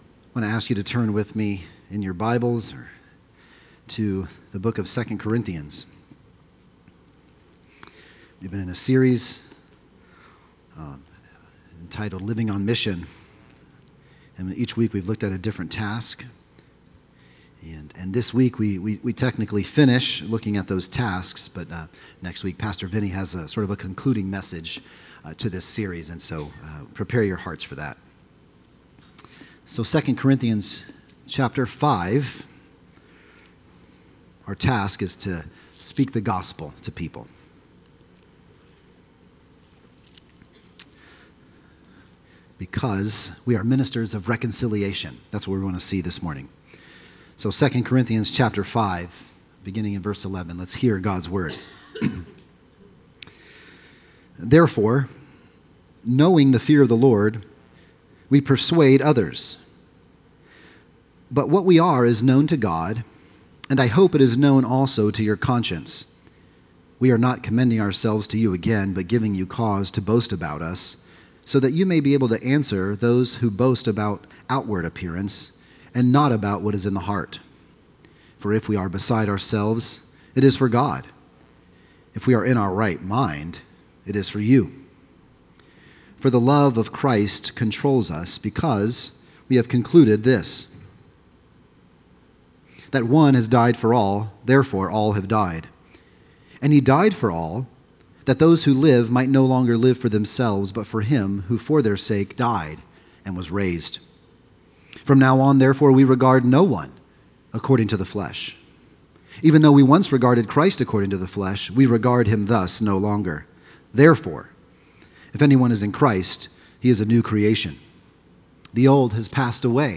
Living On Mission | Sermon Series | Crossroads Community ChurchCrossroads Community Church